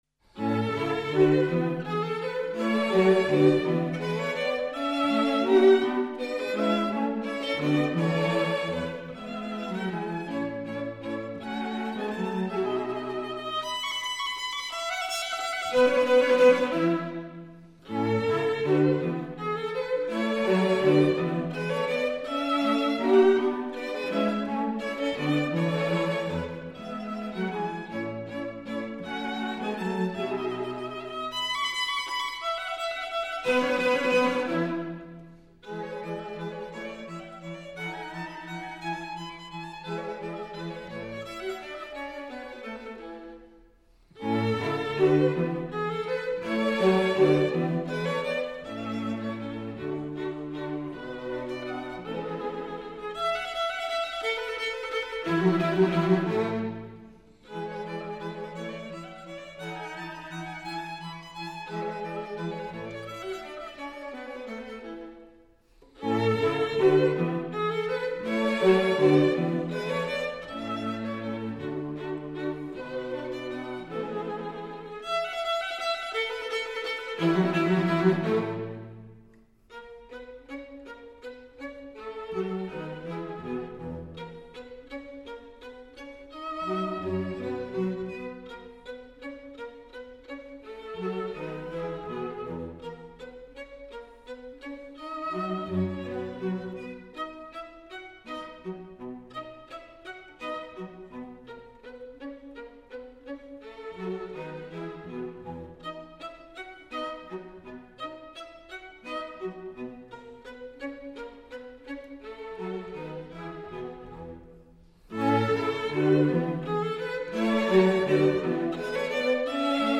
String Quartet in A major
Menuetto